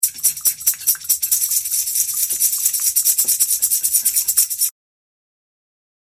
ｼｬﾝｼｬﾝｼｬﾝｼｬﾝｼｬﾝｼｬﾝｼｬﾝｼｬﾝ
ﾁｬﾎﾟﾁｬﾎﾟﾁｬﾎﾟﾁｬﾎﾟ